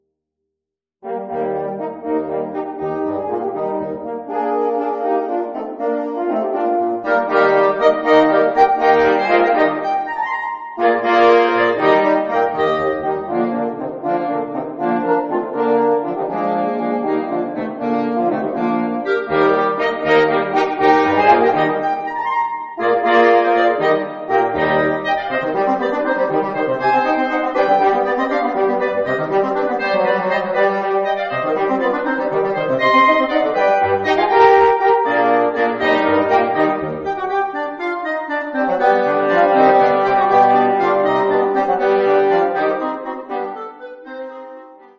2 clarinets, 2 horns, 2 bassoons
(Audio generated by Sibelius/NotePerformer)